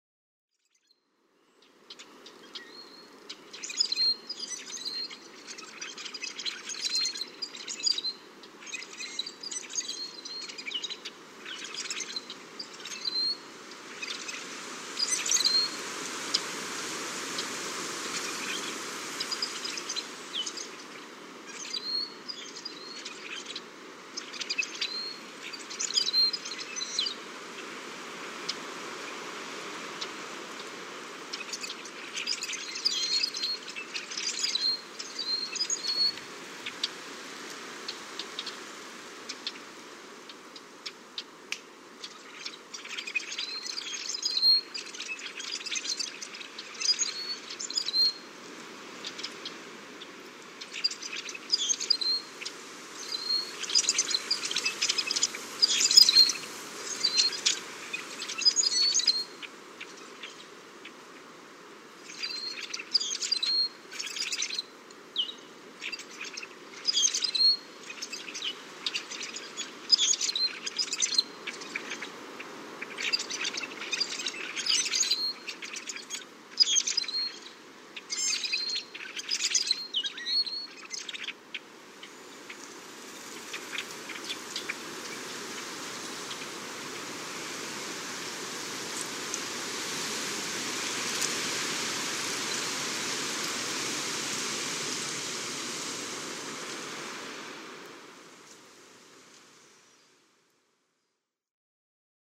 – Tous ces Carouges à épaulettes dans les marais de Sorel, au bord du Saint Laurent
GRAND VENT À SOREL